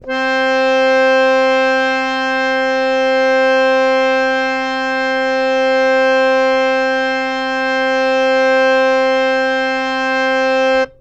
interactive-fretboard / samples / harmonium / C4.wav
C4.wav